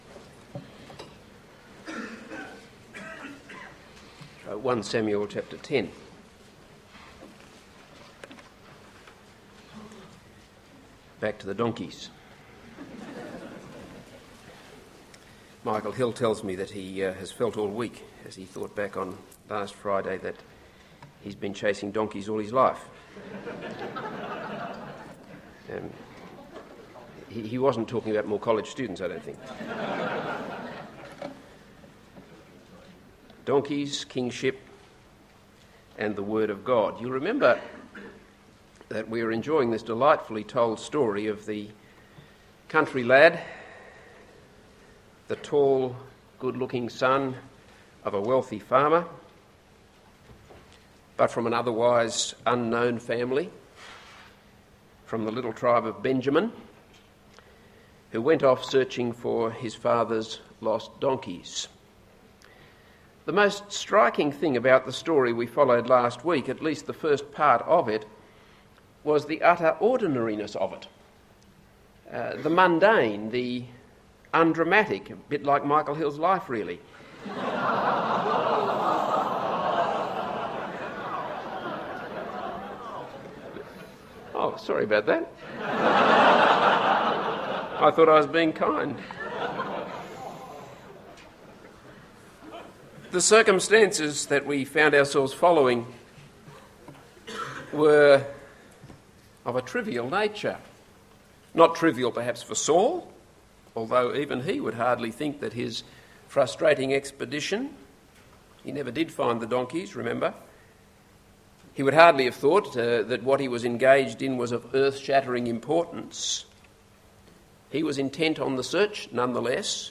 This is a sermon on 1 Samuel 10.